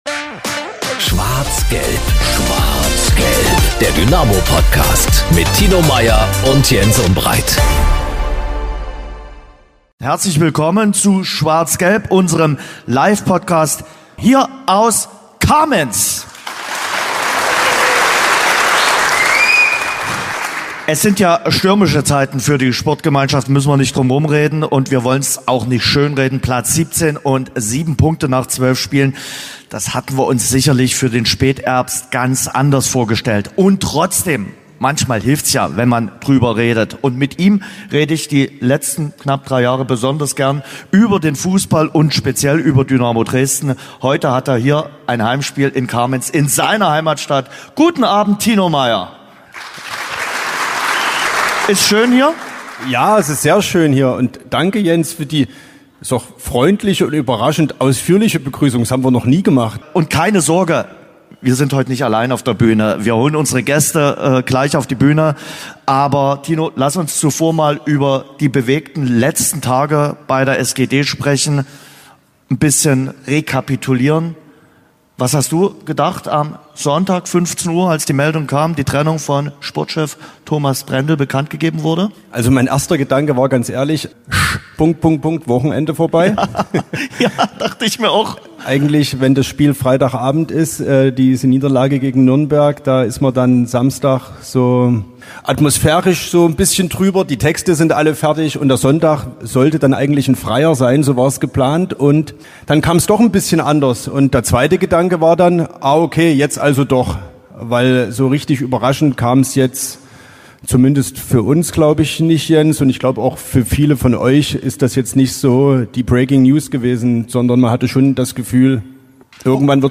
Der Dynamo-Livetalk